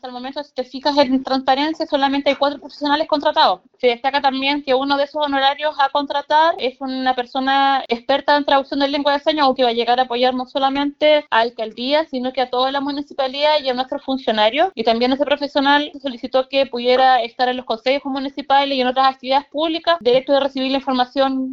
concejala-barbara-caceres.mp3